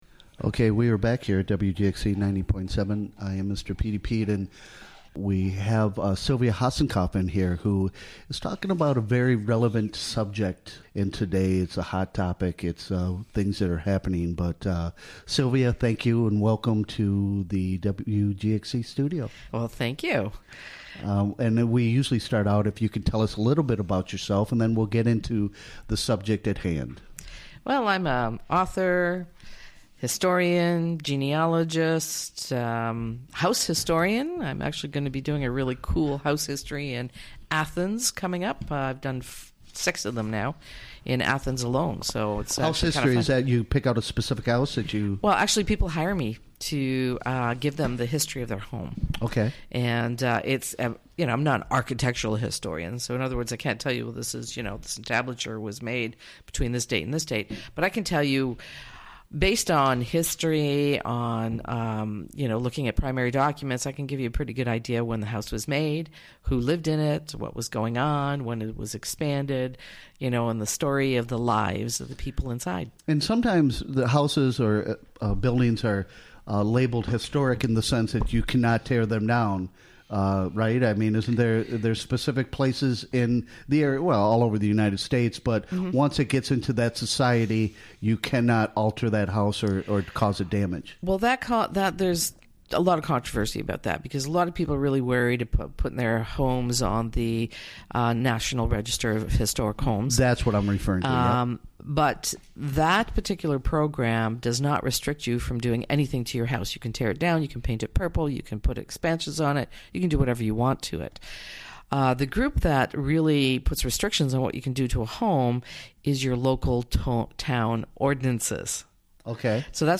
This regular monthly feature was recorded live during the WGXC Morning Show of Tue., April 17, 2018.